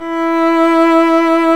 Index of /90_sSampleCDs/Roland L-CD702/VOL-1/STR_Violin 1-3vb/STR_Vln1 _ marc
STR VLN MT08.wav